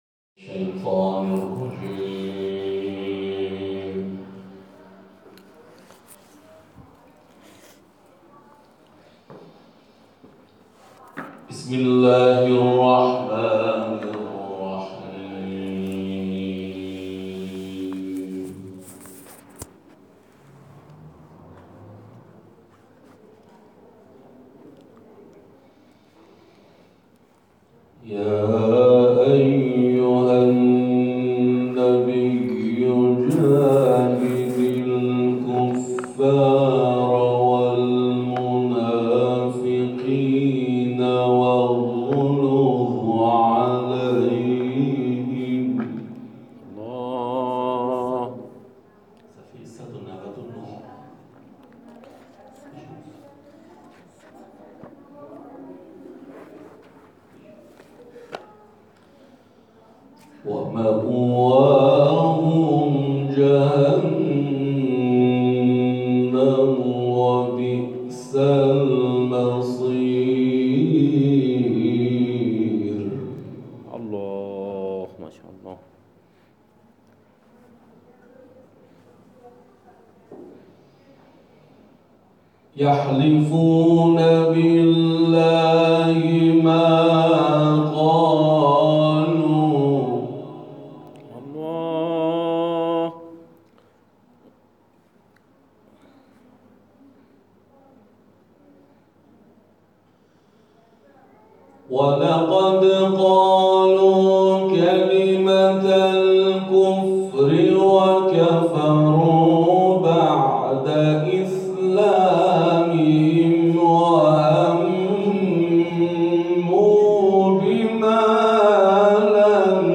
تلاوت در کانال‌های قرآنی/